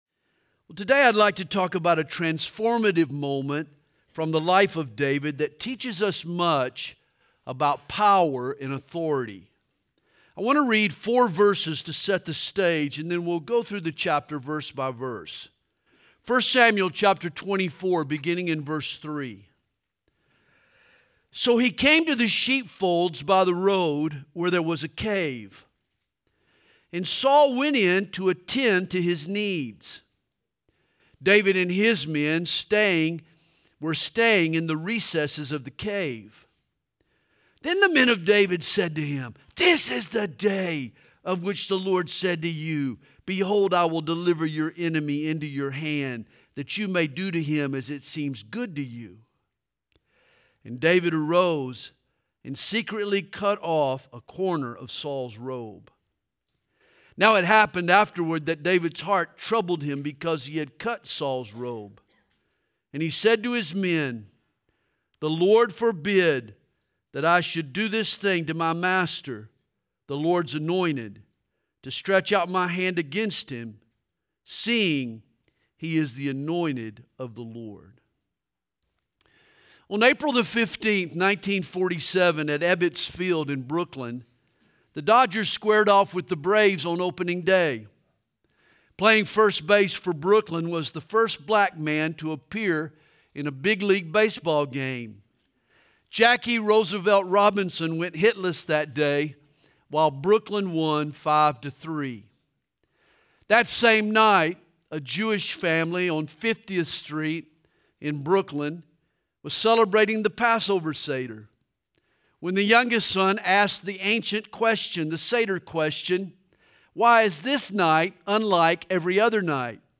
Home » Sermons » Just Because You Can, Doesn’t Mean You Should
2025 DSPC Conference: Pastors & Leaders